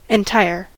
entire: Wikimedia Commons US English Pronunciations
En-us-entire.WAV